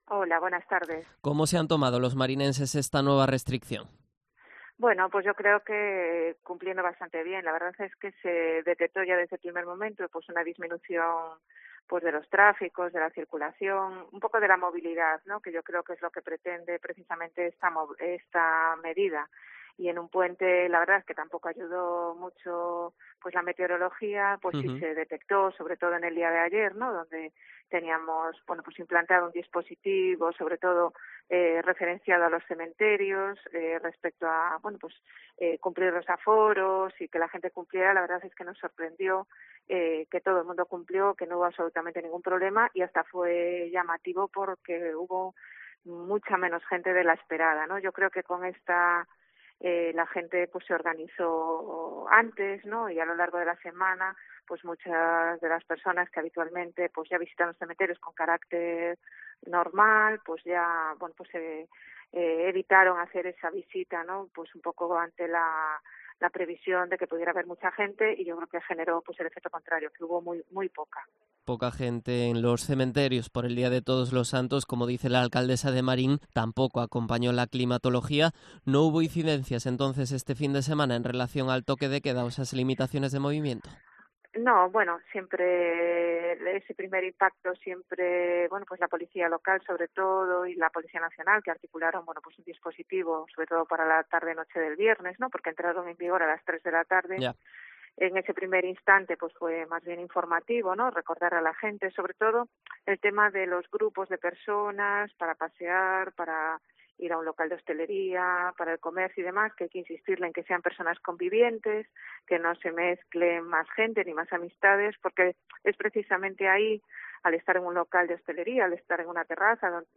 Entrevista a María Ramallo, alcaldesa de Marín